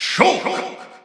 The announcer saying Shulk's name in English and Japanese releases of Super Smash Bros. 4 and Super Smash Bros. Ultimate.
Shulk_English_Announcer_SSB4-SSBU.wav